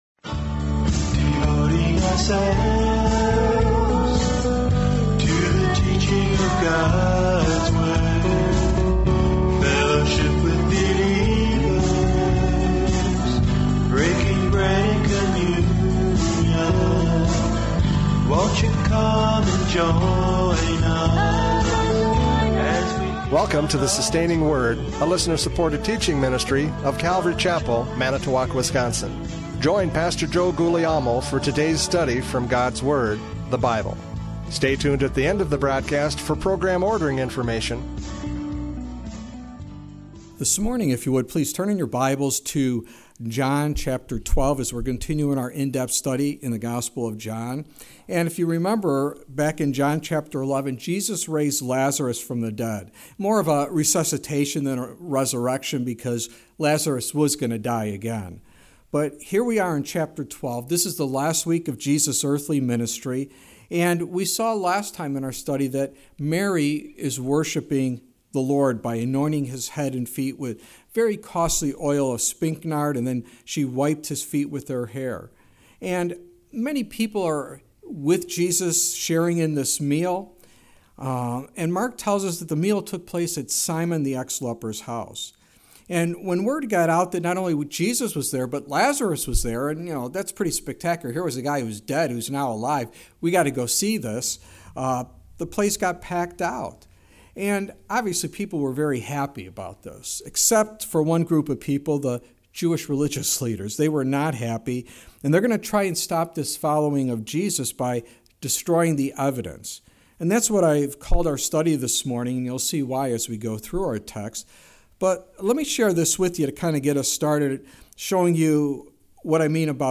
John 12:9-11 Service Type: Radio Programs « Prophecy Update 2024 Convergence!